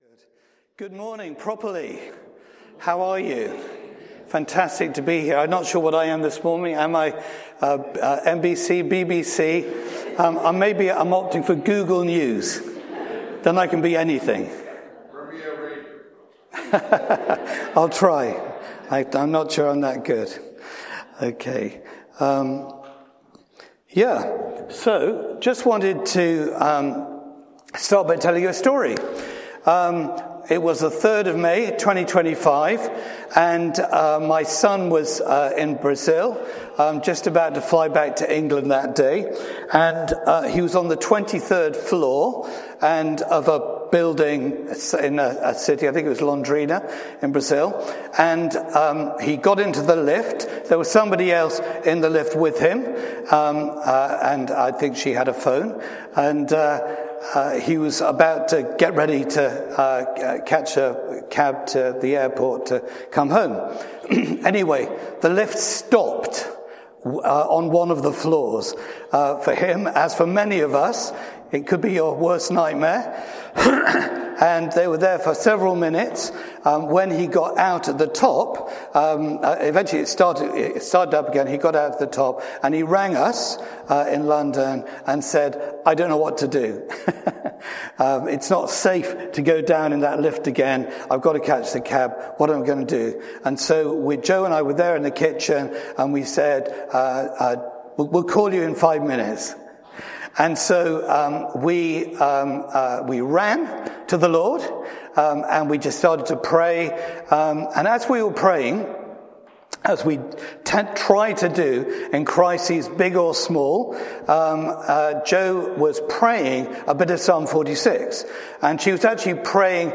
Bassett Street Sermons